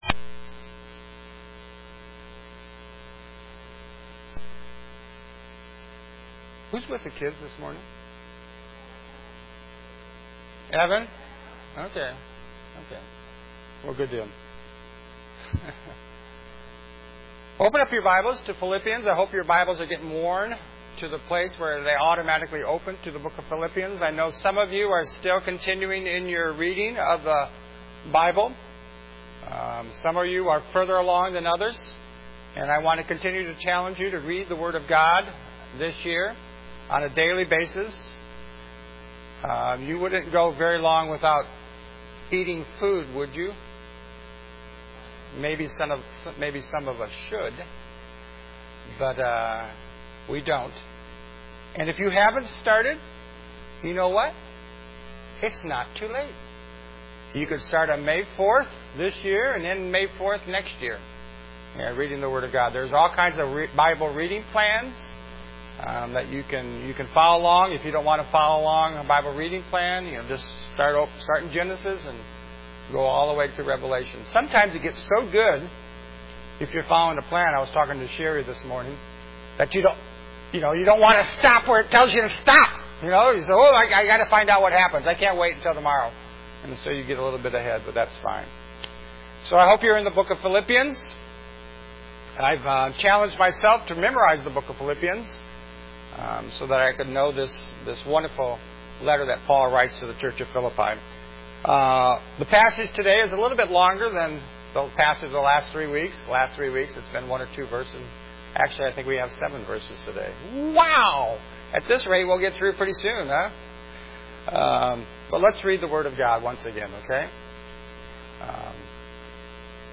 may_4_am_sermon.mp3